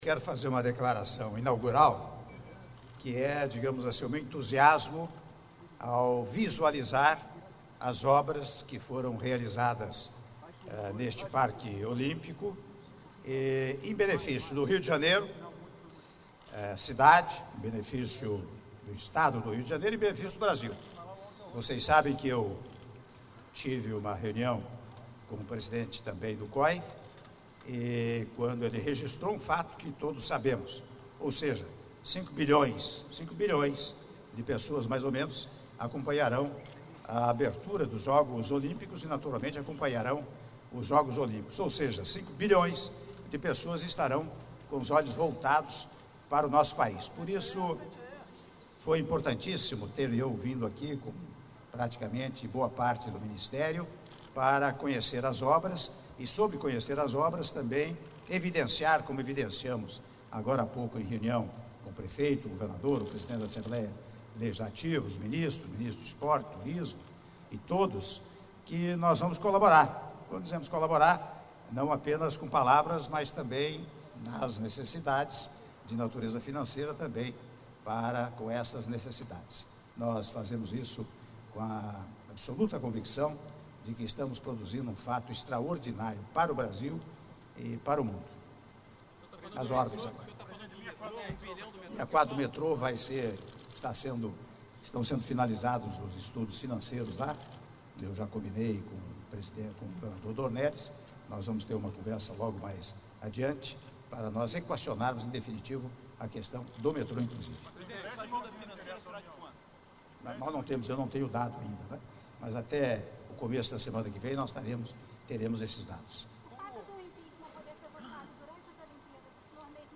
Áudio da entrevista coletiva concedida pelo Presidente da República em exercício, Michel Temer, após visita ao Parque Olímpico (04min10s) - Rio de Janeiro/RJ